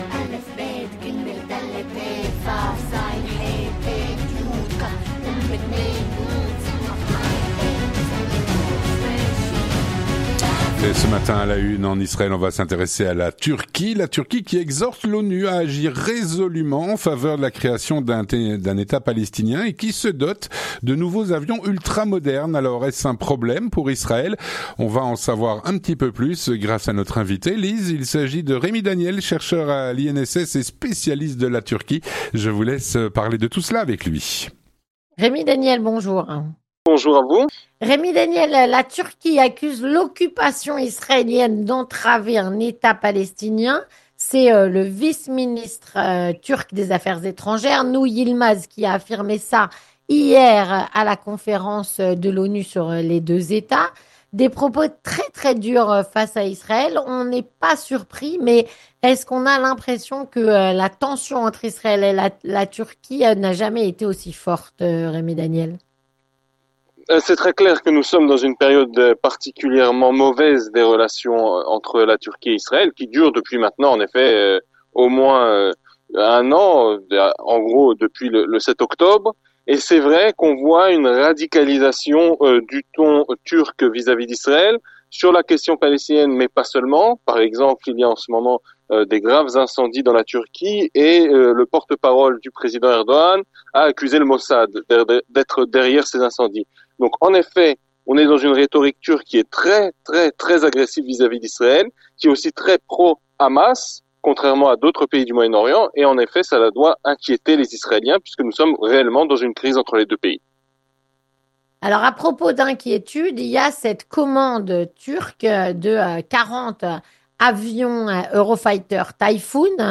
3. Les Chroniques de la Matinale